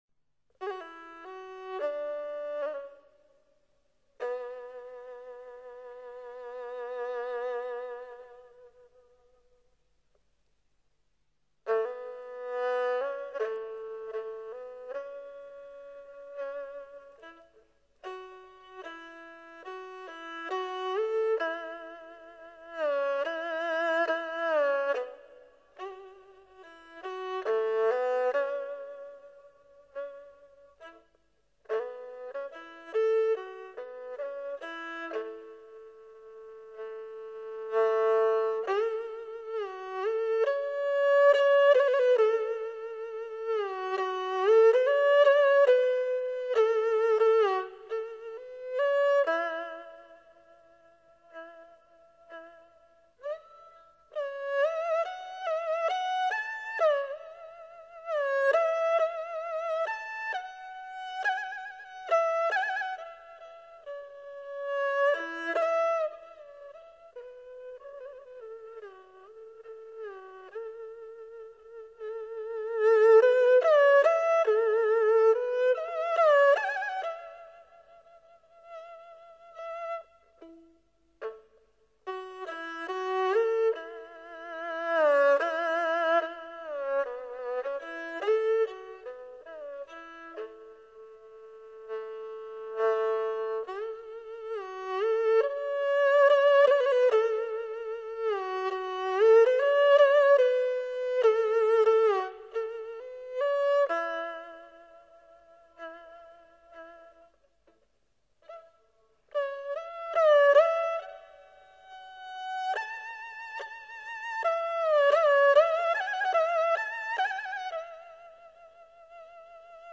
只见她缓操琴弓、指揉细弦，忽而倾身俯耳，忽而闭目沉迷，忽而昂首仰醉，二胡音色似人声，悲情呜咽，闻者无不酸楚怅然。